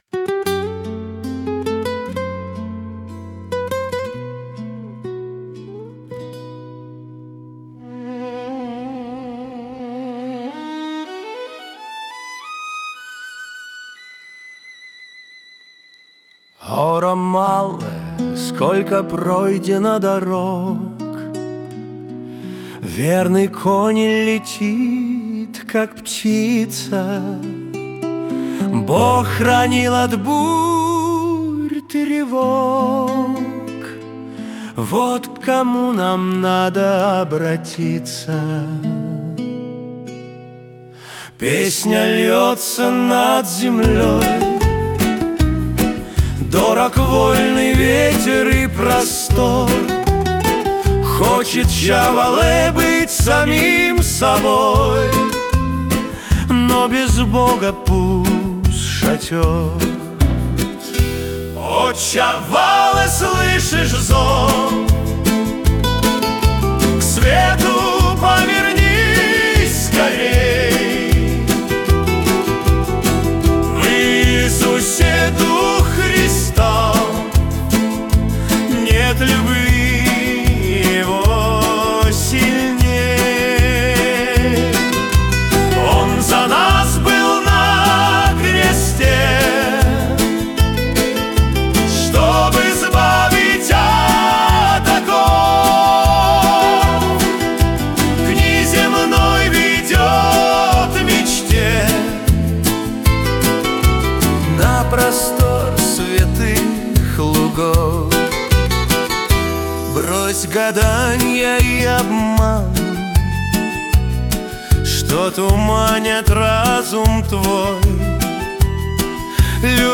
песня ai
213 просмотров 740 прослушиваний 61 скачиваний BPM: 134